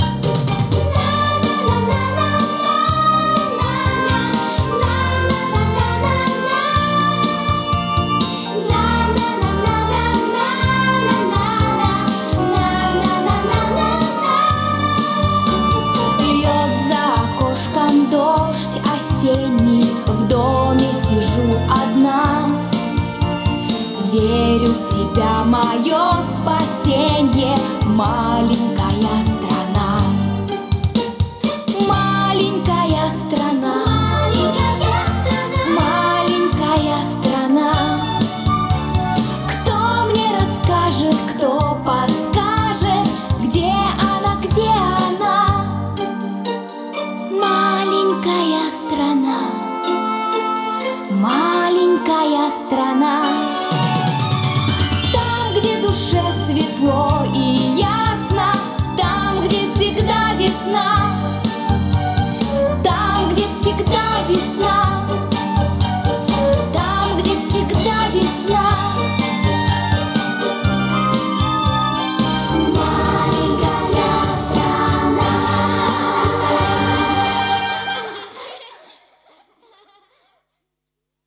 Там обычно дети пели, а не злобные гномы ...